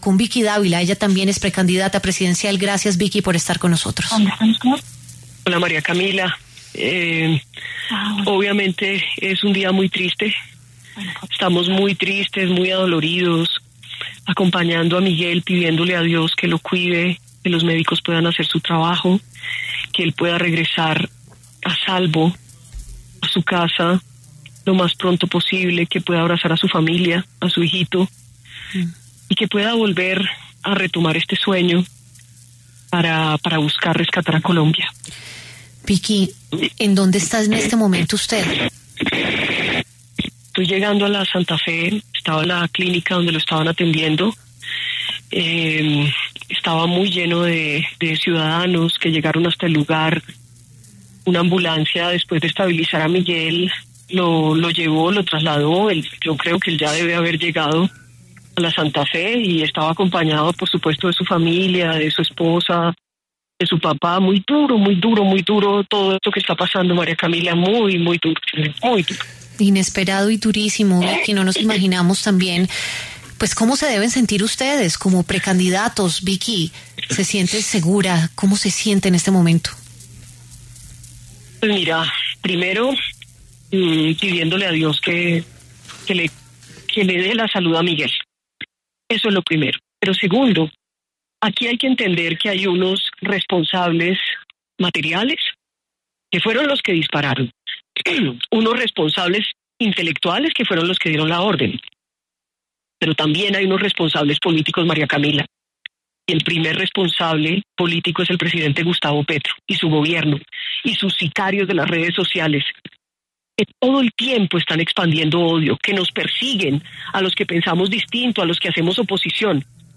Vicky Dávila, precandidata presidencial, conversó con W Radio sobre el atentado con arma de fuego que sufrió el precandidato presidencial del Centro Democrático Miguel Uribe Turbay en Fontibón, occidente de Bogotá.